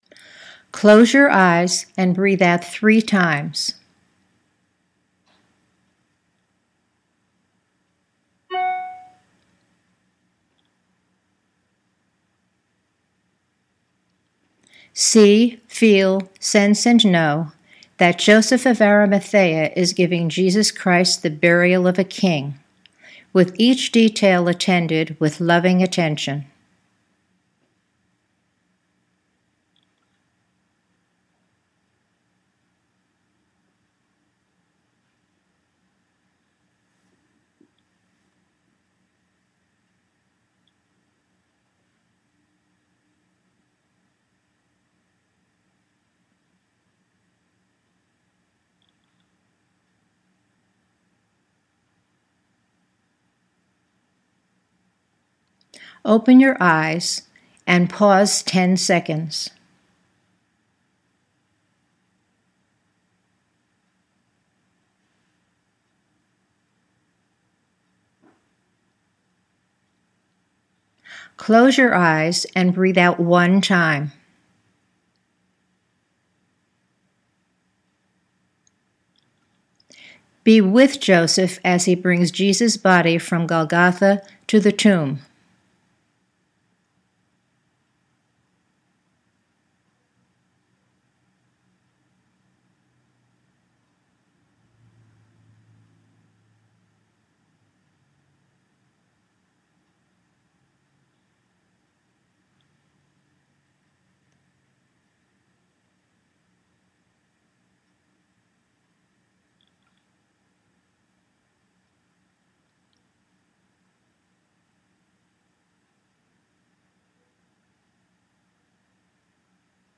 IMAGERY EXERCISE: Joseph of Arimathea Prepares and Buries the Body of Jesus Christ